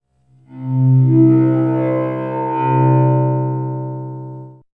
Source: Resonated natural harmonics on C (9:10-10:02)
Processing: Granulated @ 12:1 plus 3 lower harmonics
Res_Harmonic_C.aiff